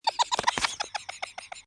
avatar_emotion_laugh.mp3